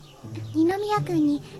本編から拾った「や」の音声を比べてみます。